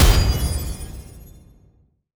rank-impact-pass-ss.wav